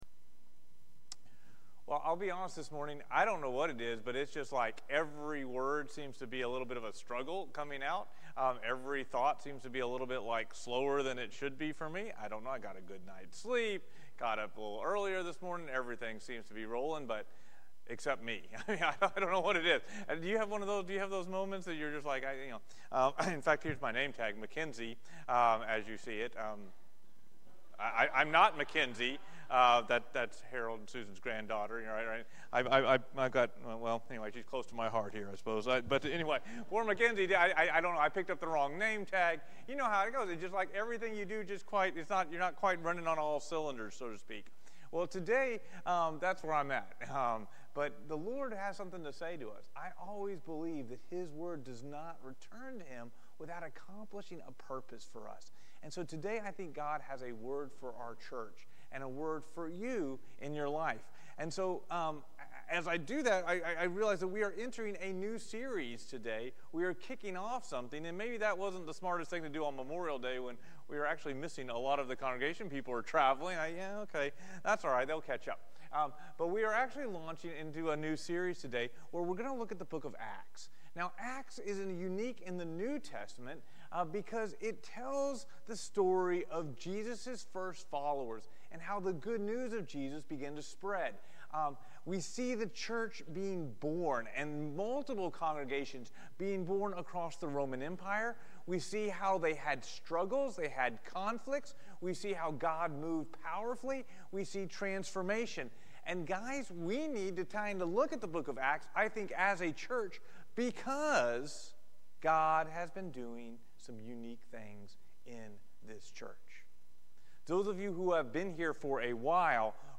Valley Sermon Podcast
Sermons from Sunday worship at Valley Baptist Church in Lutherville, MD